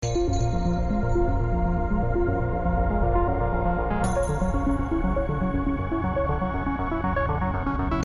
可怕的和奇怪的
描述：带有一些混响的琶音合成音序。
标签： 纳闷 吓人 琶音器
声道立体声